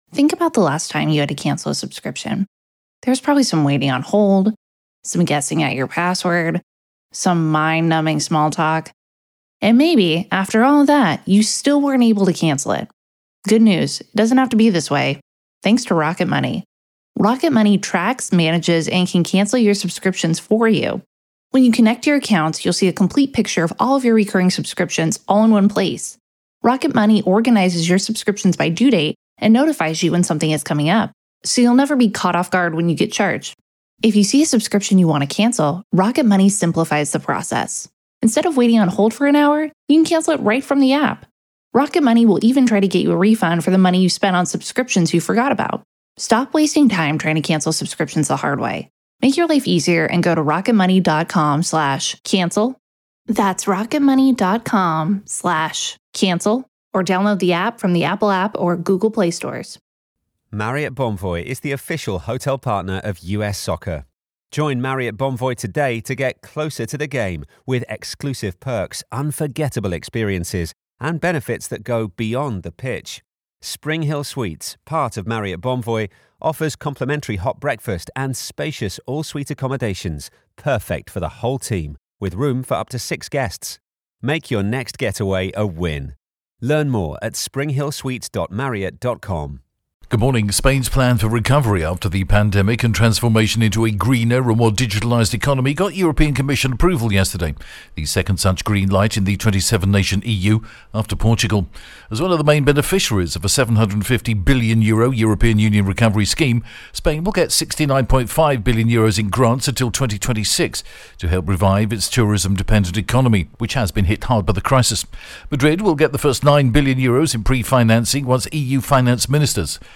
The latest Spanish news headlines in English: 17th June 2021 AM